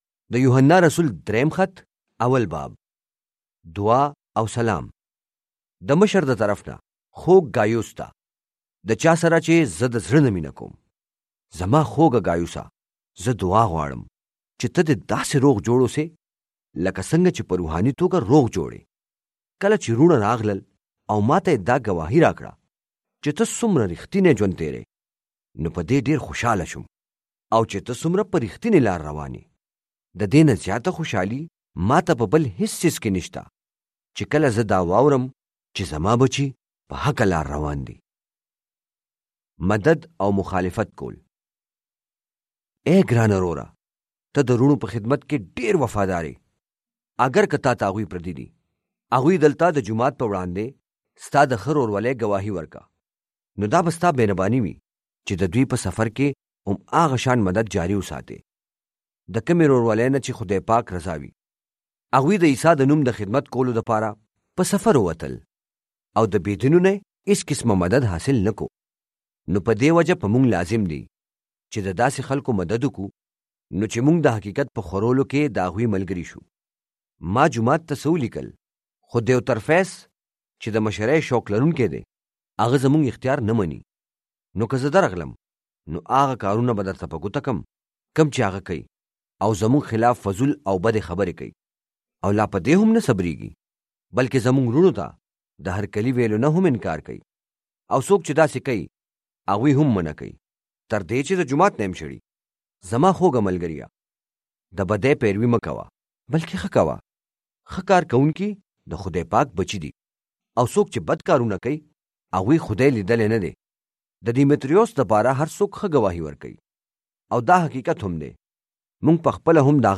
درام يوحنا به زبان پشتو